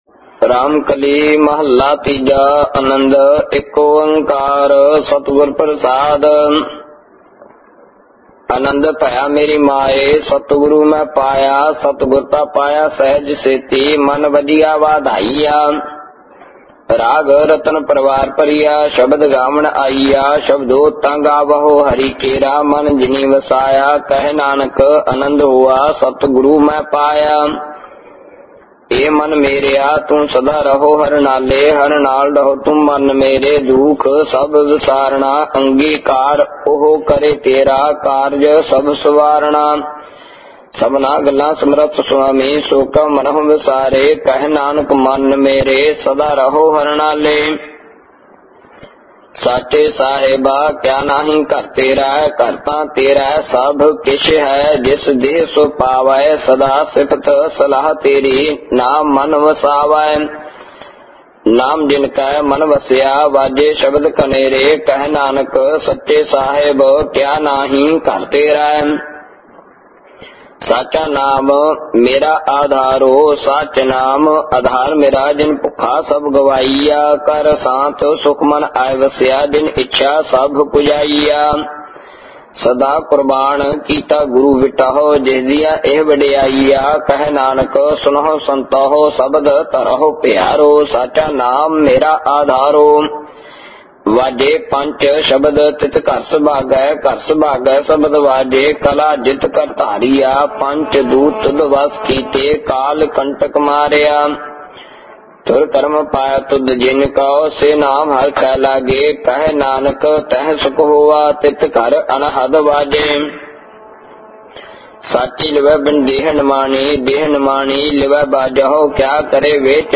Gurbani Ucharan(Paath Sahib)
Album:Anand sahib Genre: -Gurbani Ucharan Album Info